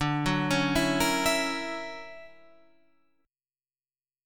D Augmented Major 9th